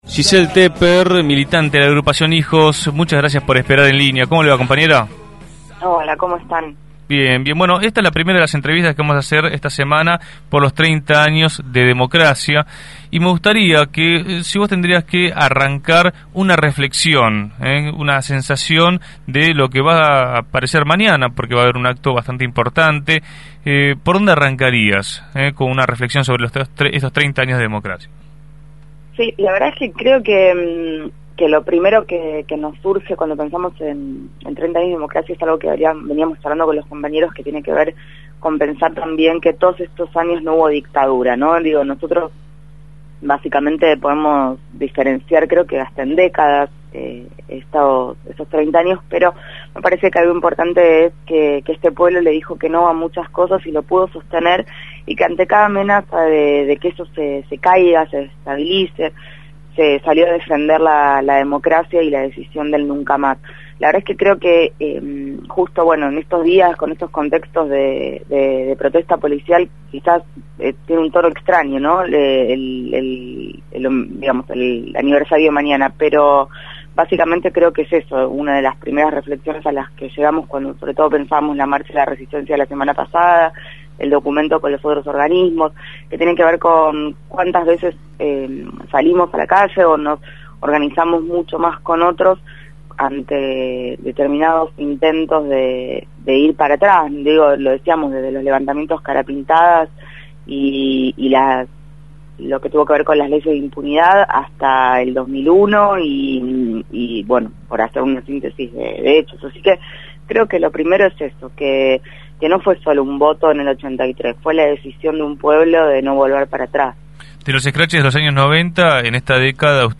fue entrevistada en Punto de Partida.